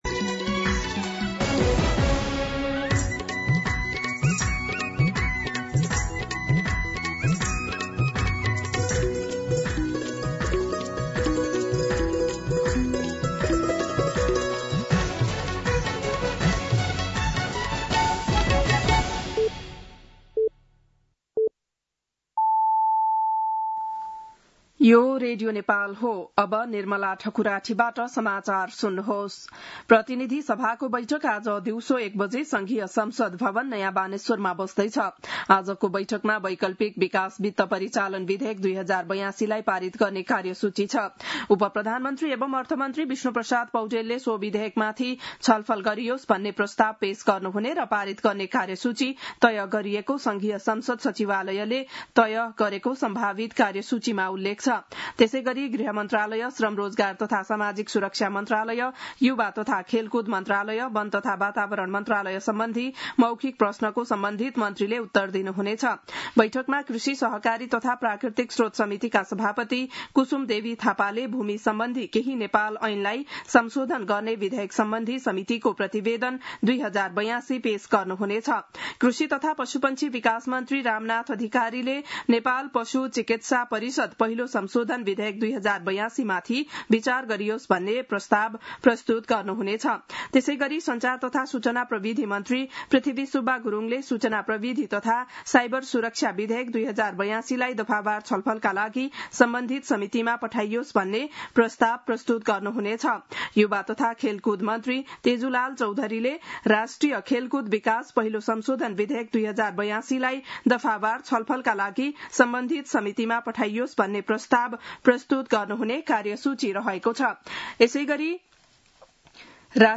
बिहान ११ बजेको नेपाली समाचार : ६ भदौ , २०८२
11-am-Nepali-News-1-2.mp3